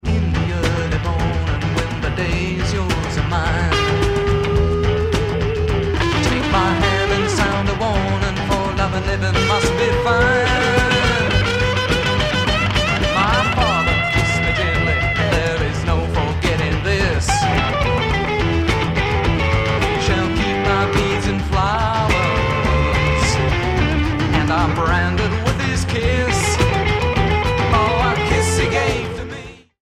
guitar, keyboards, vocals
flute, saxophone, harmonica, keyboards, vocals
bass, vocals